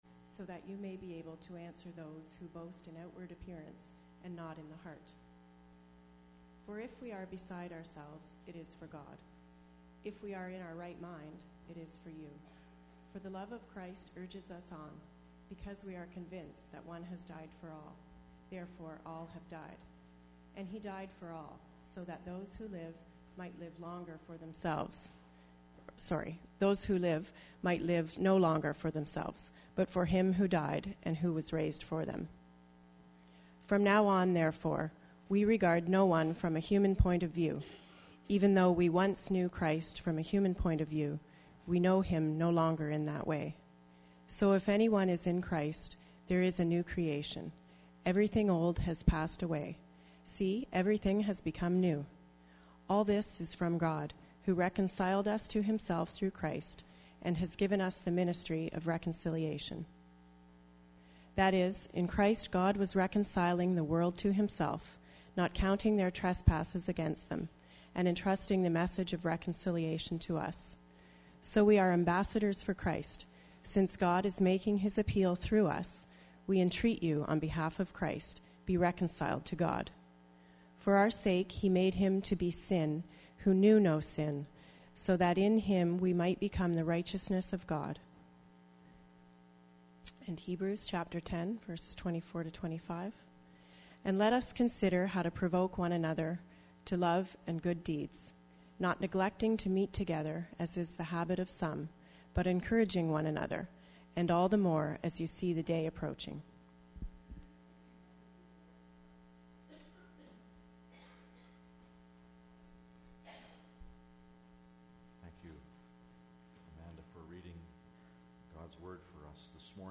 Listen to Sunday sermons online and download the Order of Service.